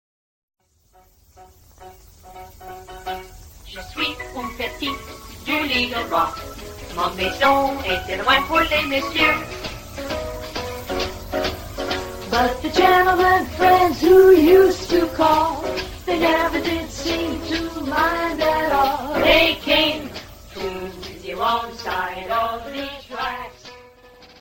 Unveröffentlichte Probeaufnahme
Los Angeles, 1952